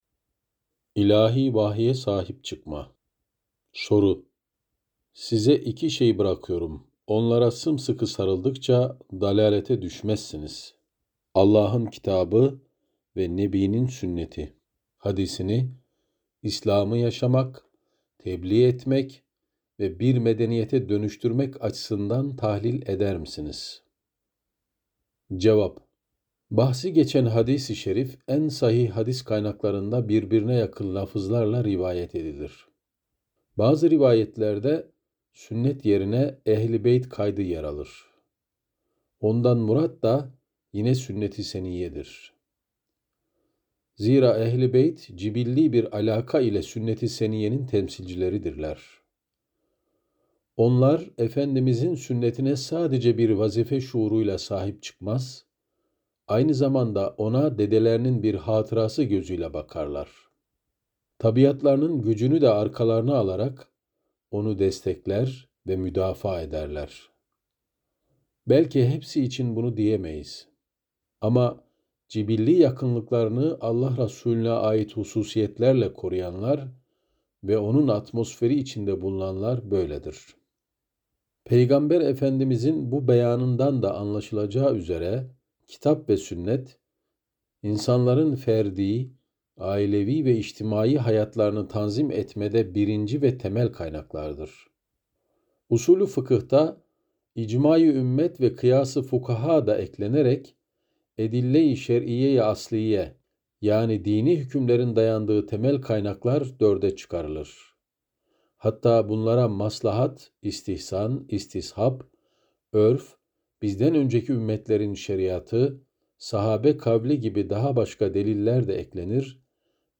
İlahi Vahye Sahip Çıkma - Fethullah Gülen Hocaefendi'nin Sohbetleri